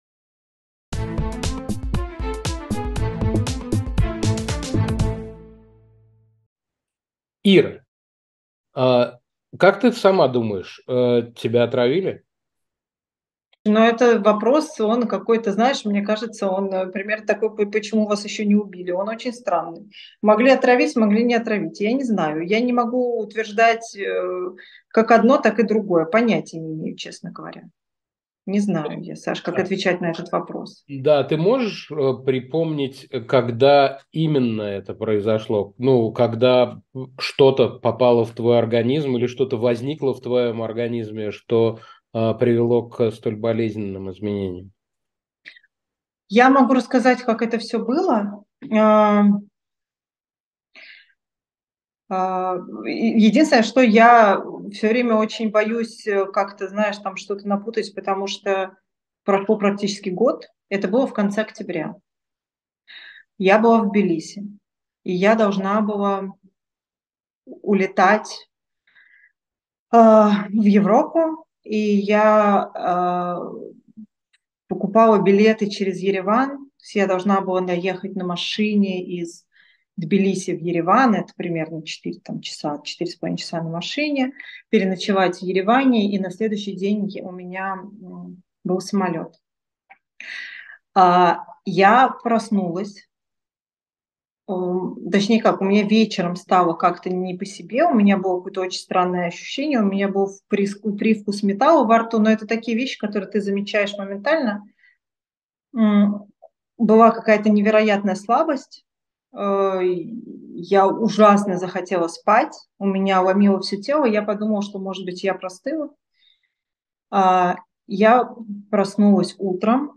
Ведущий - Александр Плющев